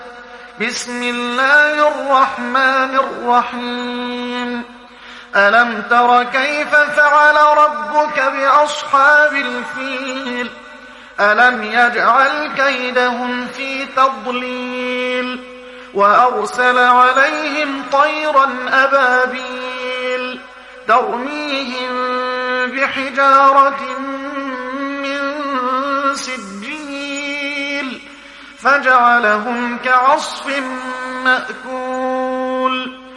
تحميل سورة الفيل mp3 بصوت محمد حسان برواية حفص عن عاصم, تحميل استماع القرآن الكريم على الجوال mp3 كاملا بروابط مباشرة وسريعة